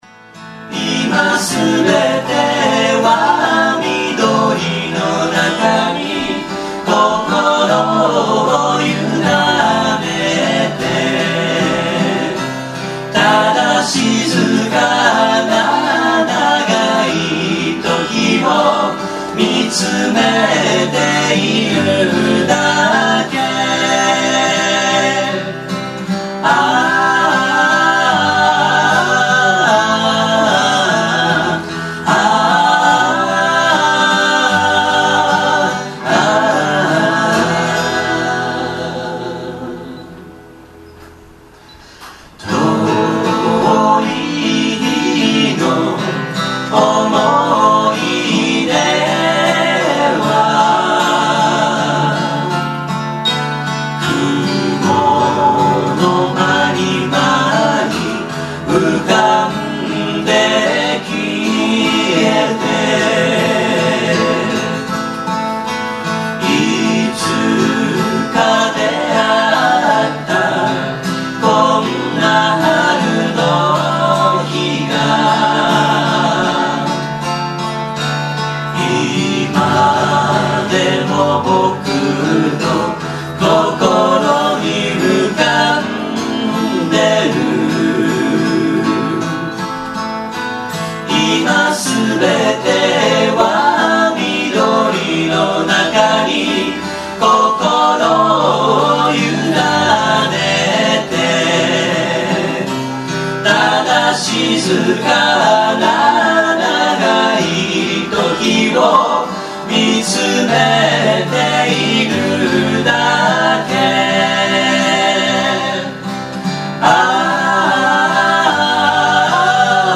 東京都府中市　「ライブシアターFlight」
社会人バンド・コンベンション